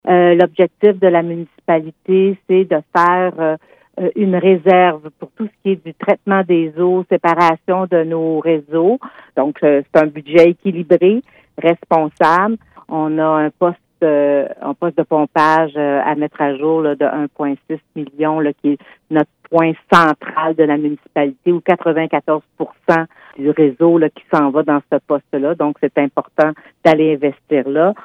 La mairesse de Cowansville, Sylvie Beauregard : Cowansville, budget 2025 responsable, 17.12.24_Beauregard, clip Pour ce qui est du programme triennal d’immobilisation 2025-2027, il prévoit des investissements de 60 M$, dont 15,5 M$ l’an prochain.